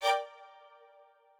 strings5_22.ogg